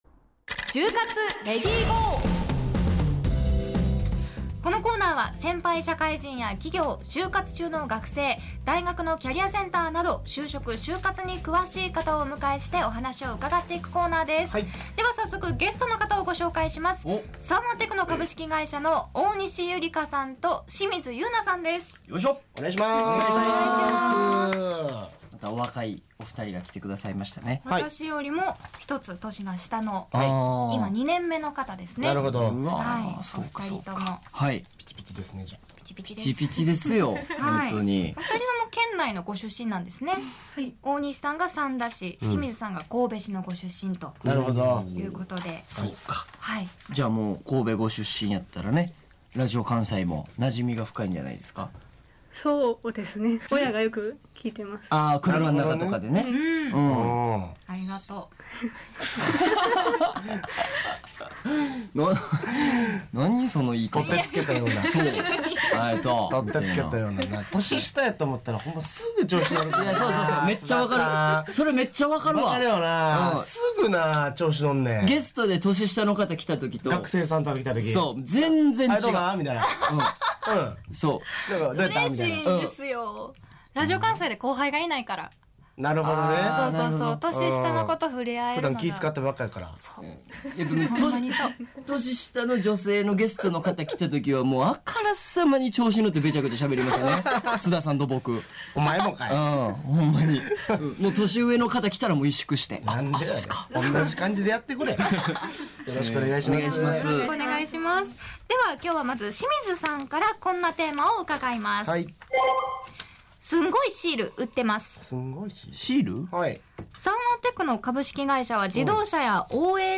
就活トークを展開した